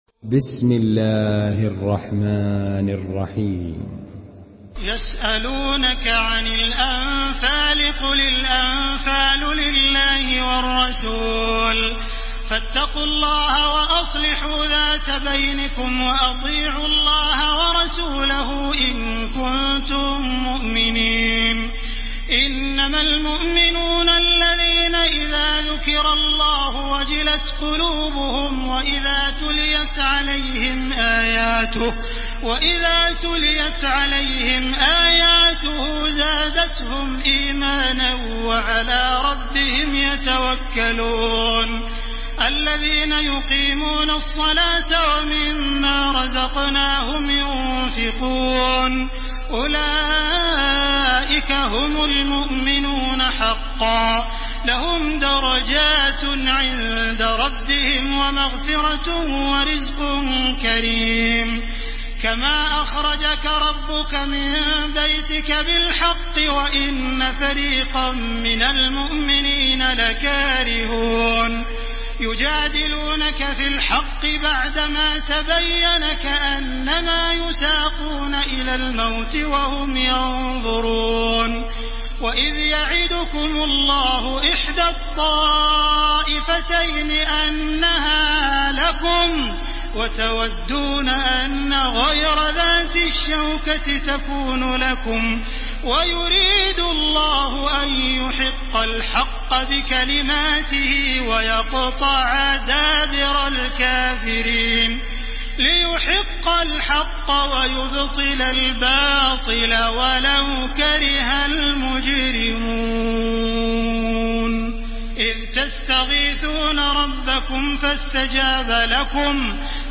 تحميل سورة الأنفال بصوت تراويح الحرم المكي 1430
مرتل حفص عن عاصم